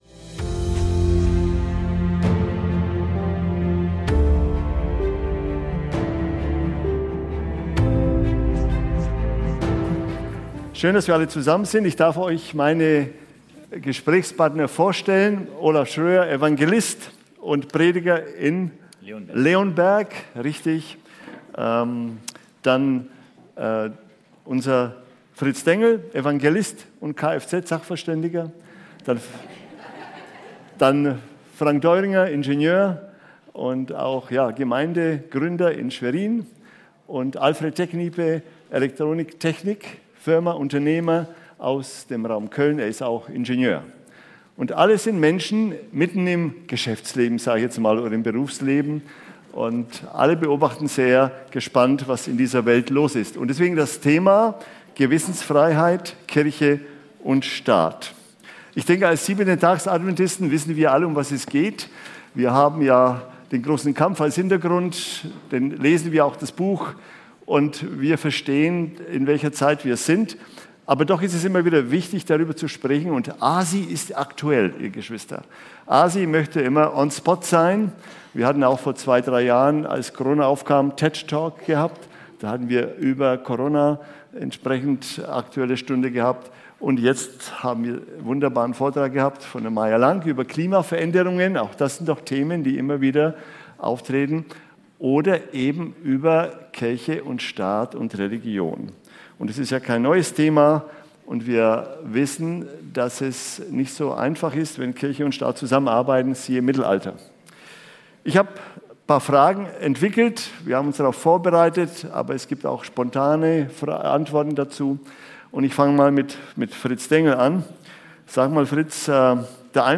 Gewissensfreiheit, Kirche und Staat (Podiumsdiskussion)